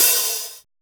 LINN OHH.wav